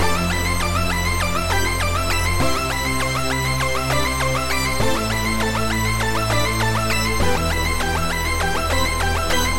风琴低音
描述：一个深沉的风琴式低音。
Tag: 137 bpm Dubstep Loops Organ Loops 2.36 MB wav Key : Unknown